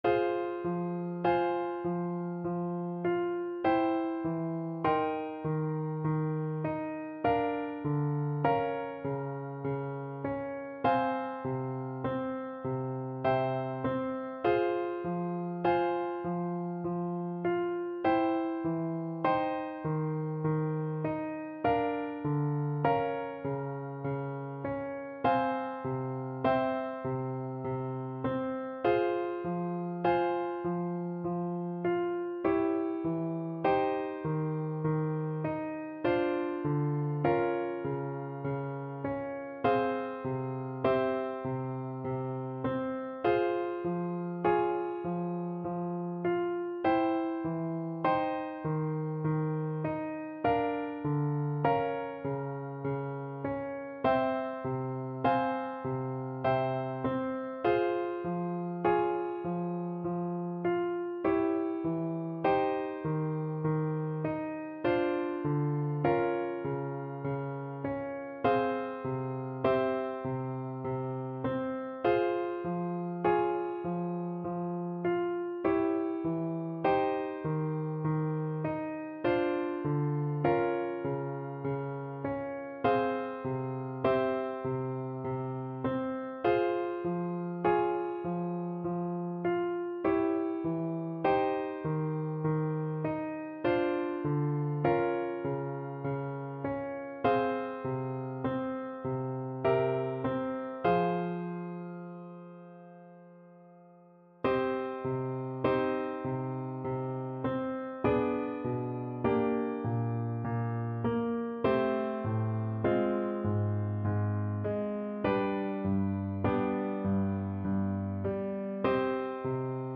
Classical Vivaldi, Antonio Piango, gemo Trumpet version
Play (or use space bar on your keyboard) Pause Music Playalong - Piano Accompaniment Playalong Band Accompaniment not yet available transpose reset tempo print settings full screen
Trumpet
F minor (Sounding Pitch) G minor (Trumpet in Bb) (View more F minor Music for Trumpet )
~ = 100 Adagio =c.50
Classical (View more Classical Trumpet Music)